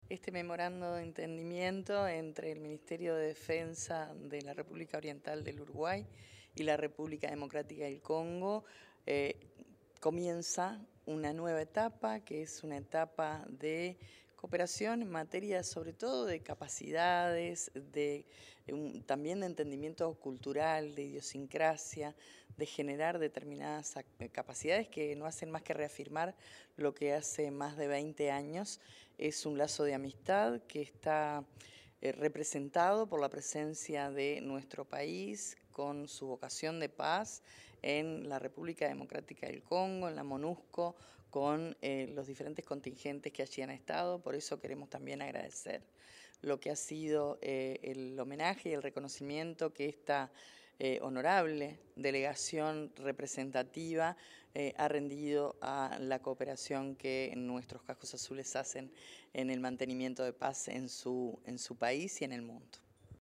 Declaraciones de la ministra de Defensa Nacional, Sandra Lazo